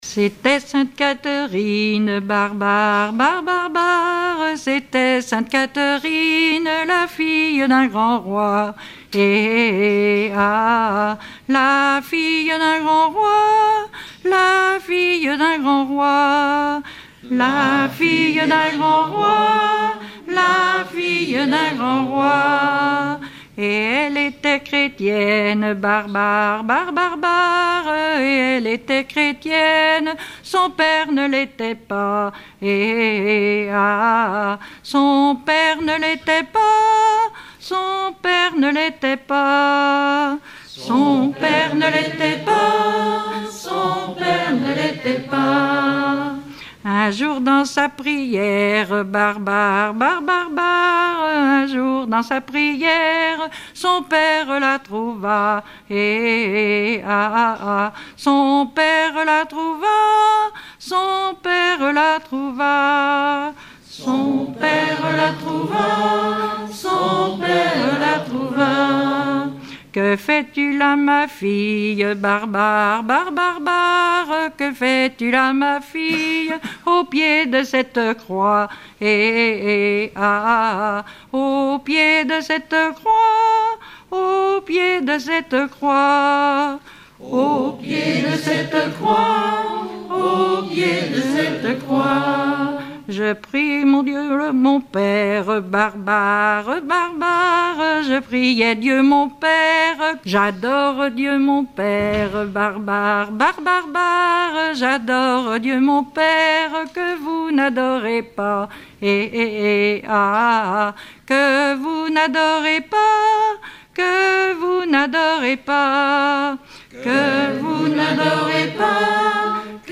Genre laisse
Chansons traditionnelles et populaires